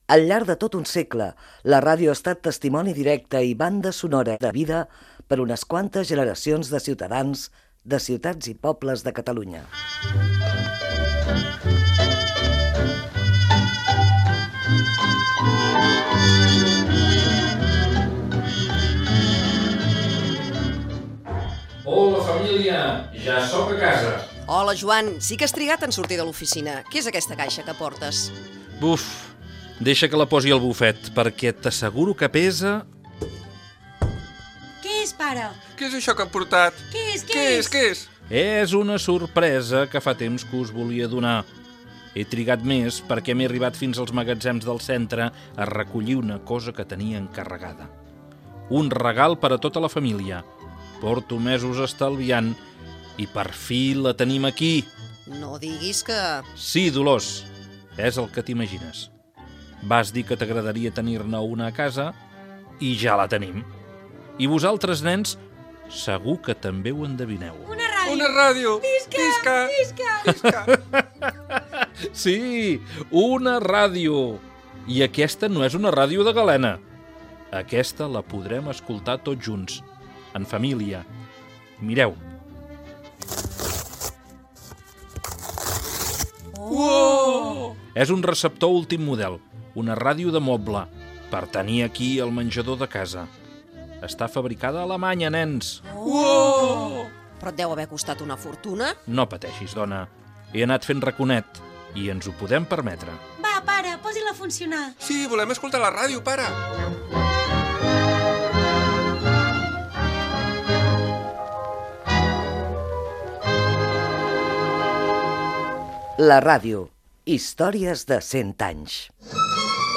Emès amb motiu del Dia Mundial de la Ràdio 2024. Fragments de Ràdio Badalona, després Ràdio Miramar. 90 anys de ràdio a Tarragona
Divulgació